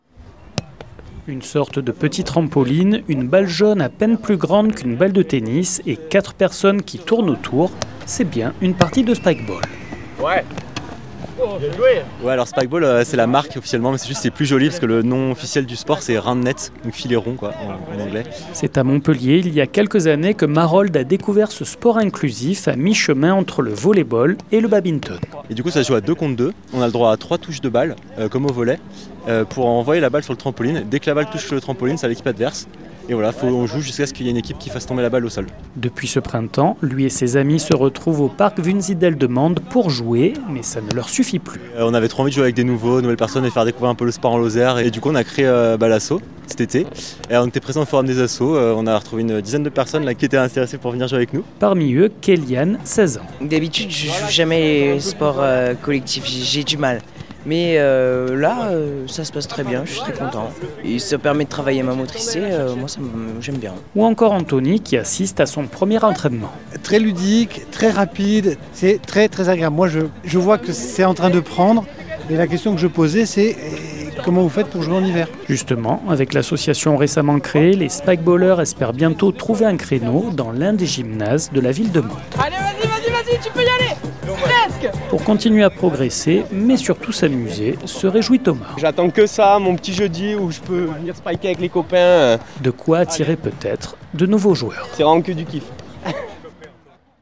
Depuis quelques mois, à Mende, une poignée de pratiquants se retrouvent chaque semaine pour taper la balle dans la bonne ambiance. Au début du mois, ces sportifs étaient même au forum des associations pour trouver de nouveaux joueurs. 48FM est allé à leur rencontre lors d’une session d’entraînement.
Reportage